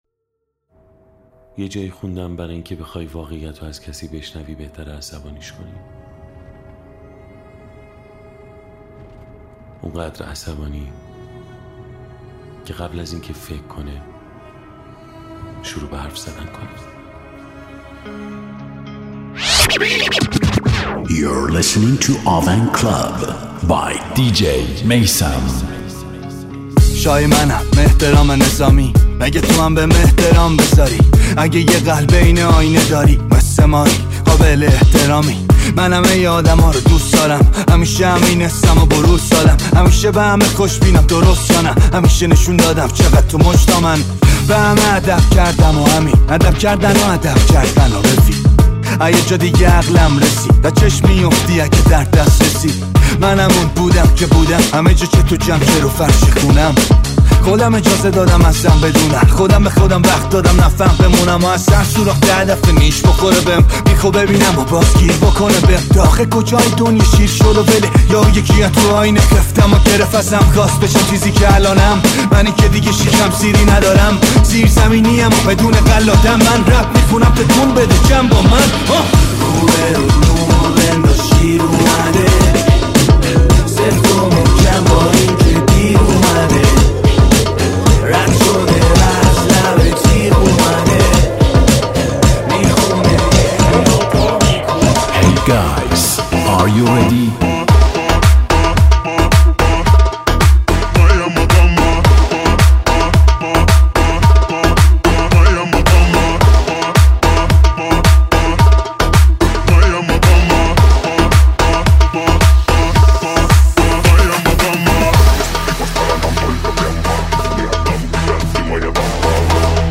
میکس بهترین آهنگ های ایرانی و خارجی ۲۰۲۱